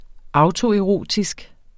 Udtale [ ˈɑwto- ]